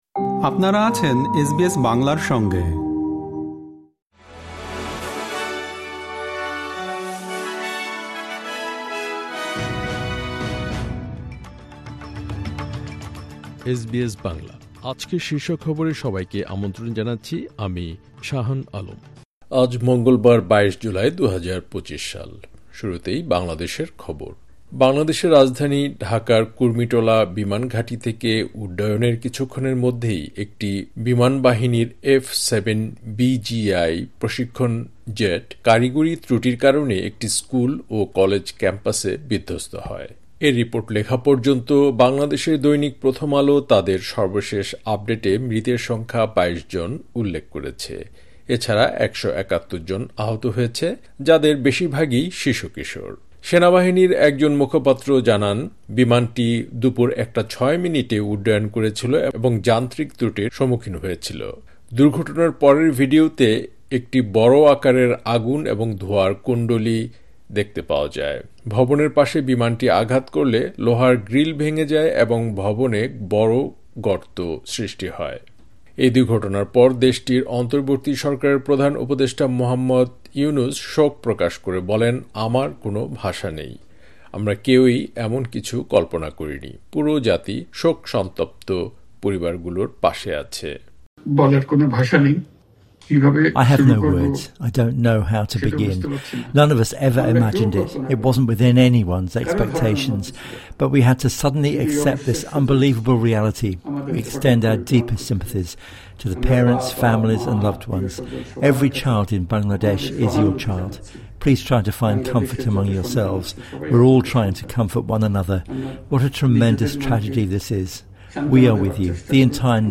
এসবিএস বাংলা শীর্ষ খবর: ২২ জুলাই, ২০২৫